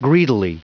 Prononciation du mot : greedily
greedily.wav